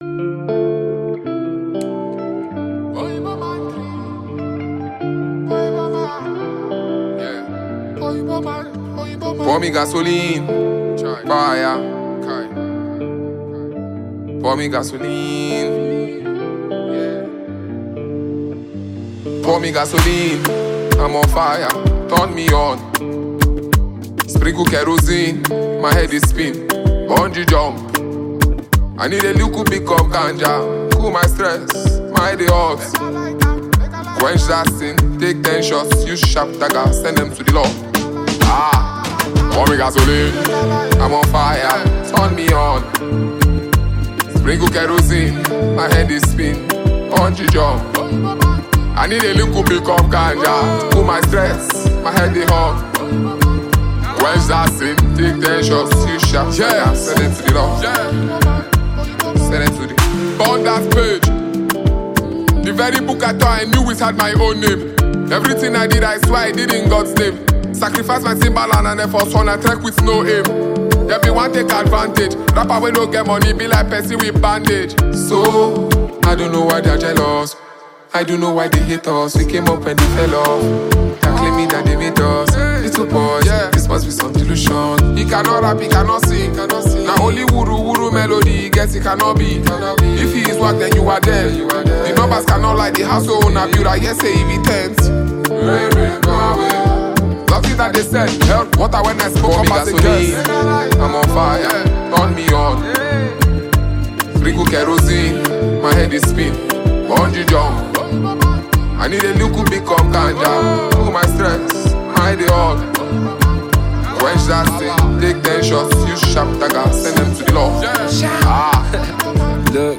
is an intense, gritty Hip Hop/Rap offering